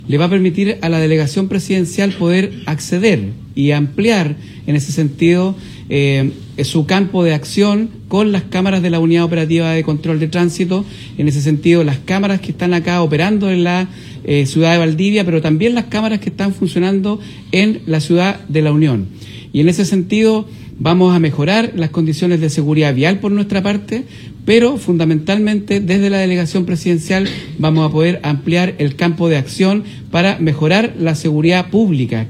El subsecretario de Transportes, Jorge Daza, mencionó que con este convenio se podrá ampliar el campo de acción para mejorar la seguridad pública que es tan importante por estos días.